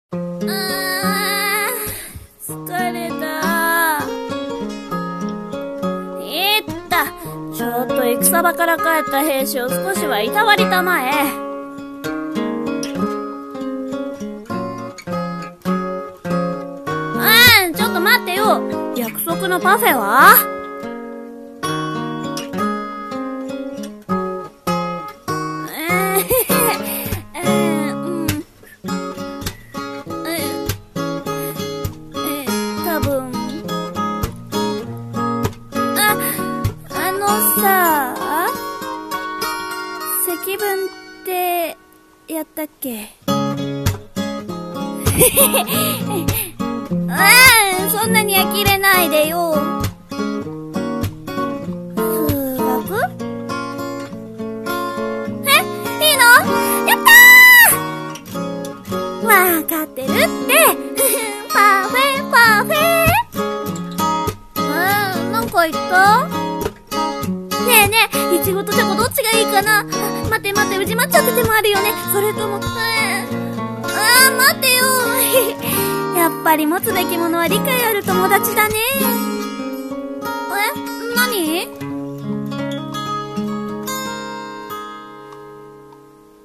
【声劇】…バカ【二人用】 / 「女の子」:素敵なコラボ者様 『男の子』:素敵なコラボ者様